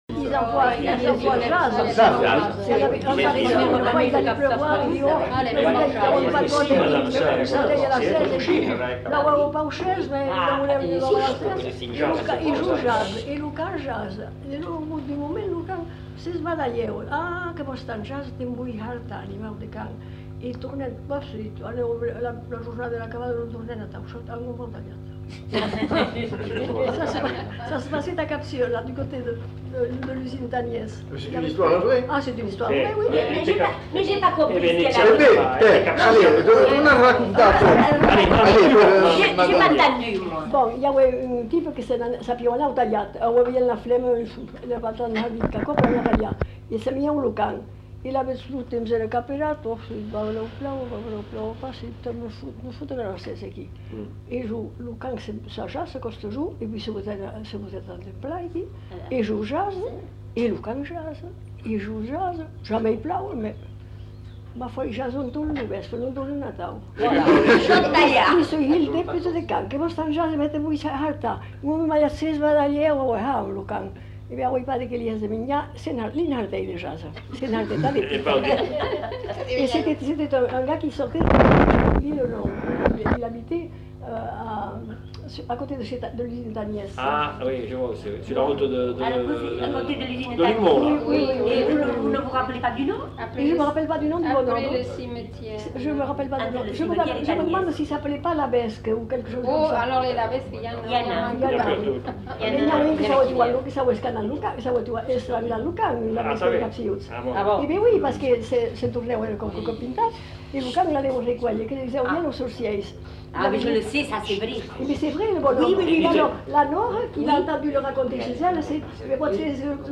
Lieu : Bazas
Genre : conte-légende-récit
Effectif : 1
Type de voix : voix de femme
Production du son : parlé
Classification : récit anecdotique